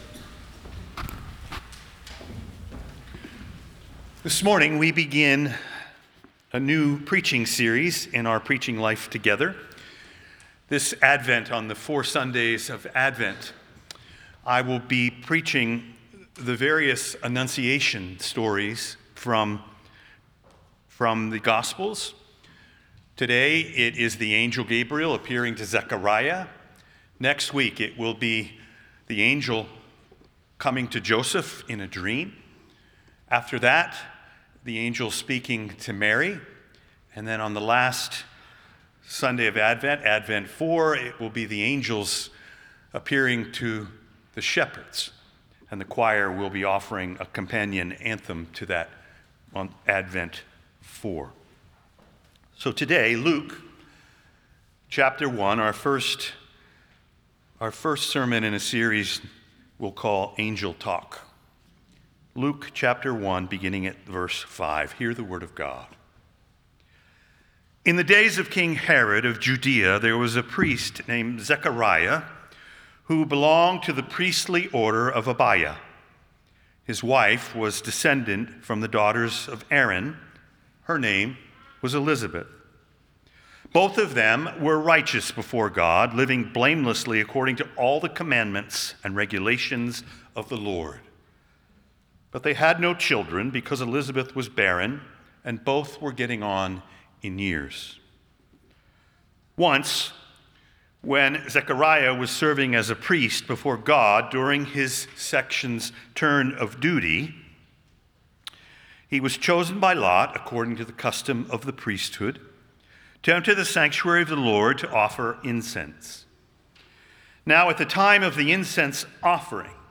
on the first Sunday of Advent